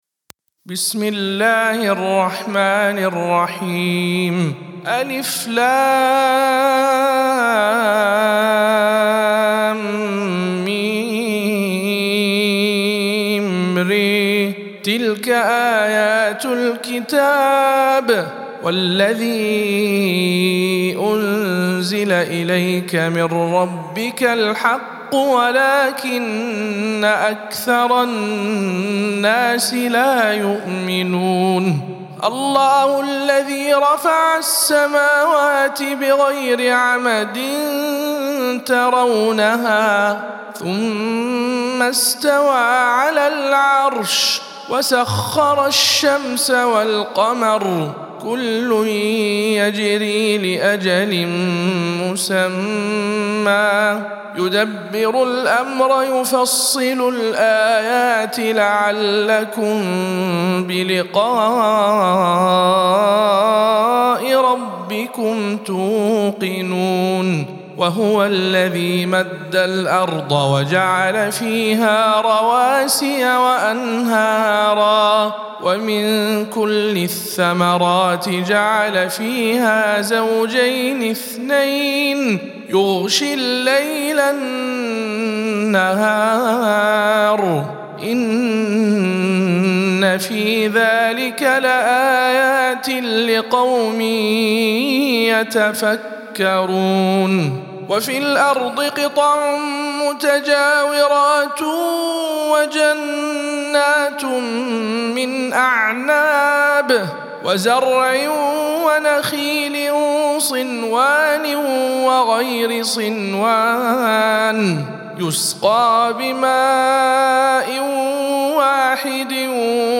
سورة الرعد - رواية ابن ذكوان عن ابن عامر